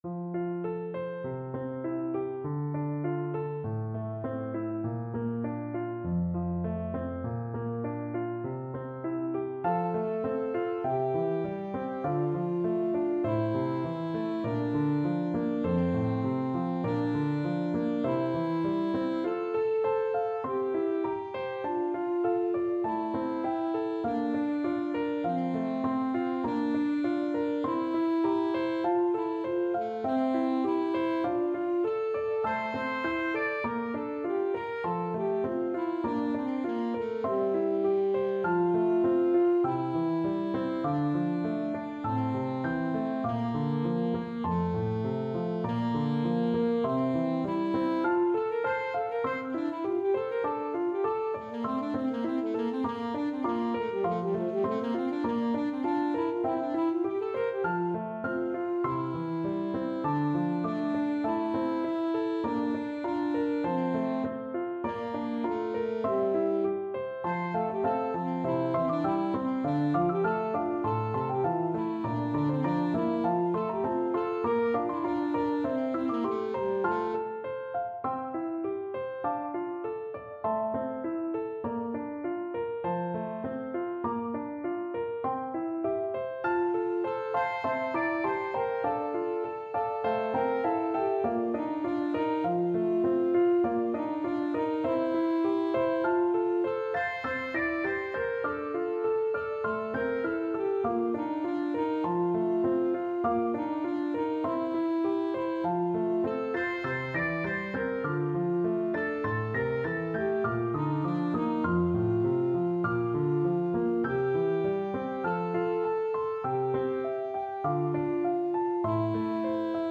Classical
FluteAlto Saxophone